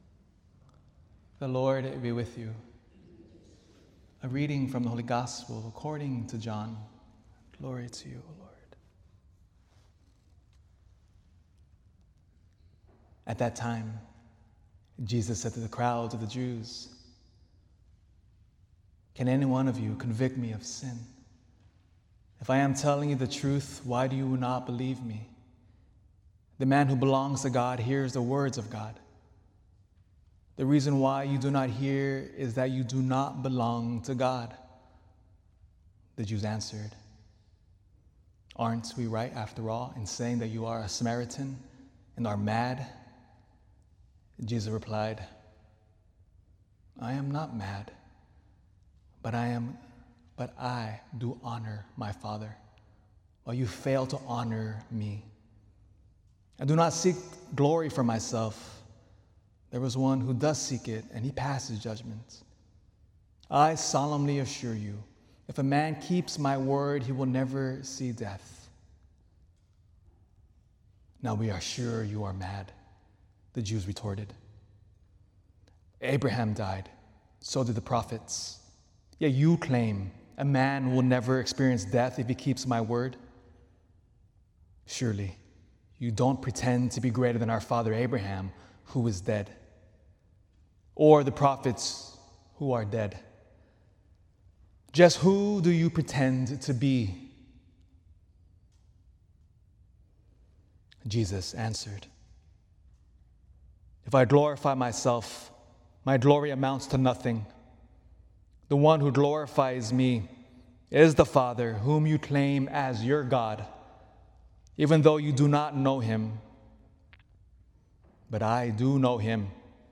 This homily was recorded at our 2PM Traditional Latin Mass